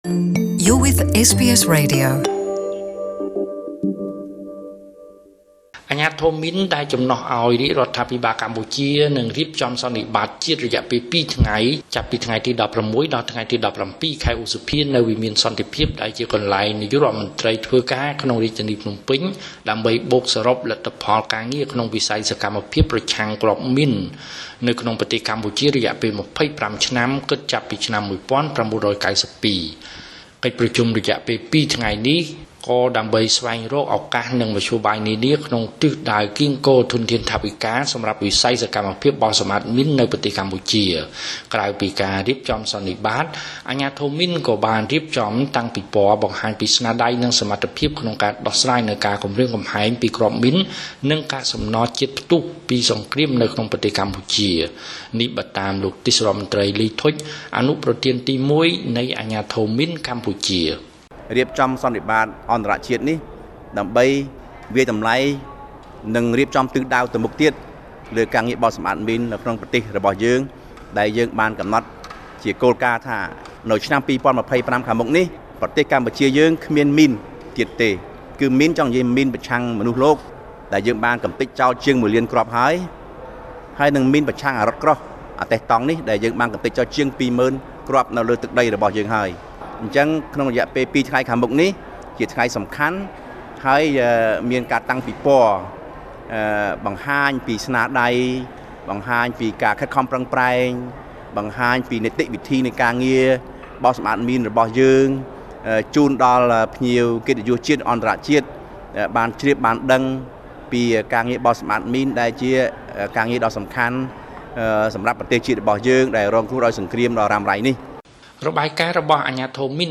( សំឡេងលោកទេសរដ្ឋមន្ត្រី លី ធុជ )